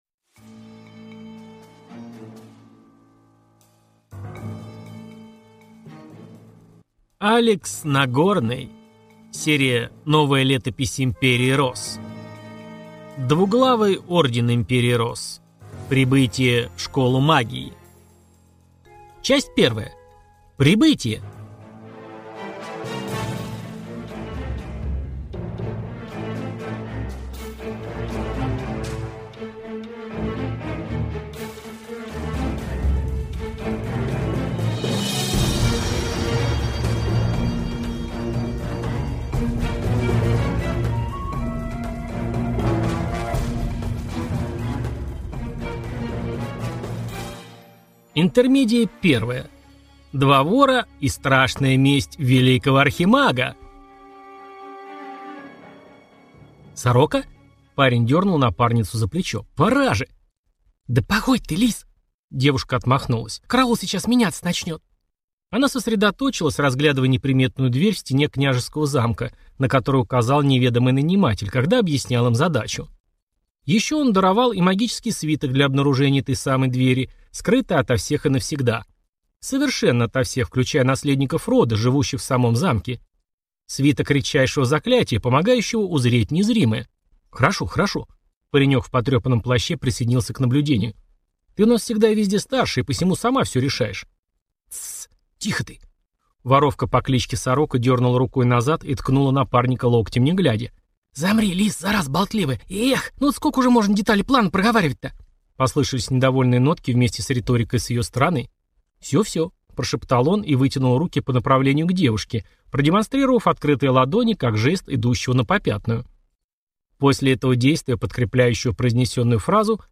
Аудиокнига Двуглавый Орден Империи Росс. Прибытие в школу магии | Библиотека аудиокниг